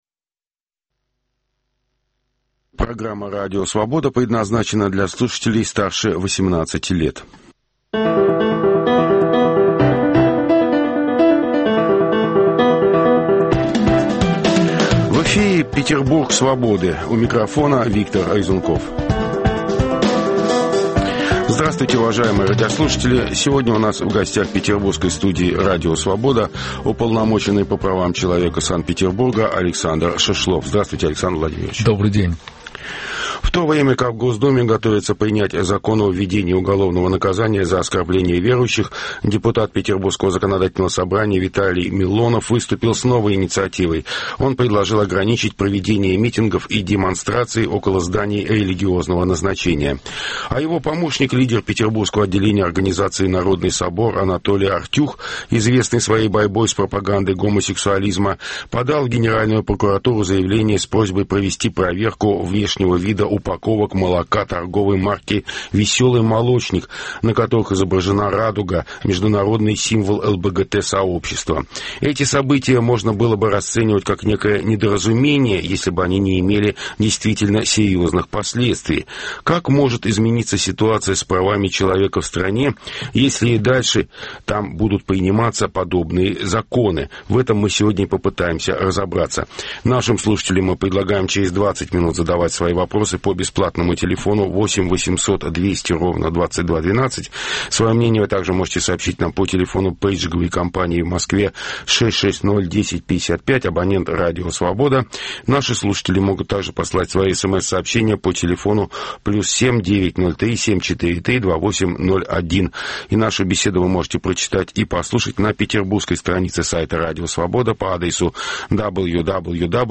Круглый стол: Петербург Свободы